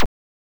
bfxr_watershoot.wav